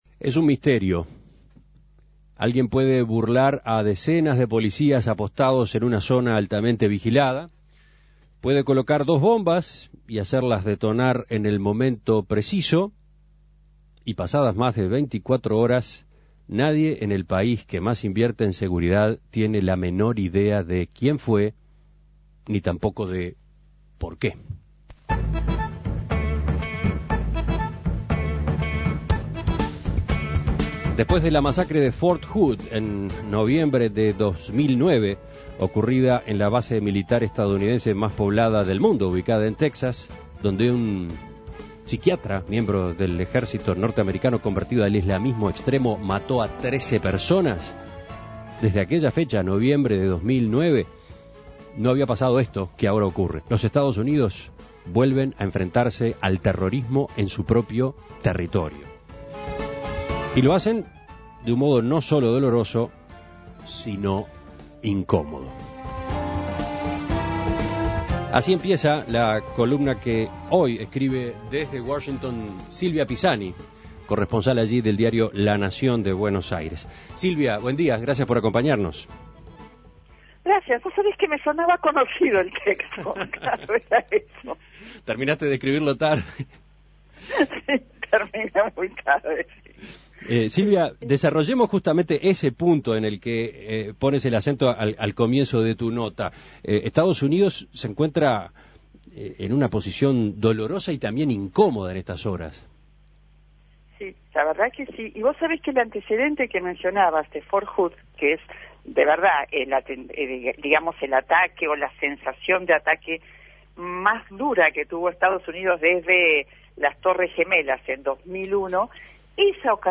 desde Washington, Estados Unidos.